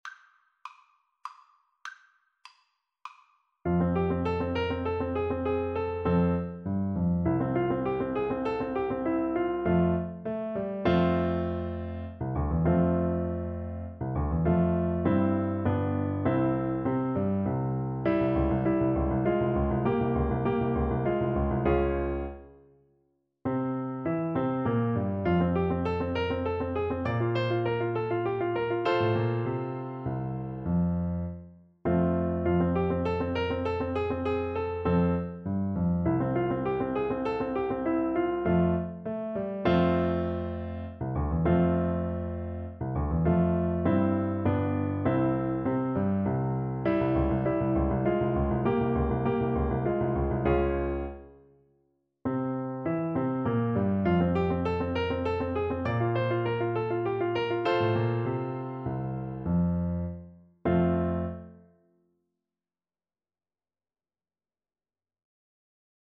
• Unlimited playalong tracks
F major (Sounding Pitch) (View more F major Music for Flute )
3/4 (View more 3/4 Music)
Classical (View more Classical Flute Music)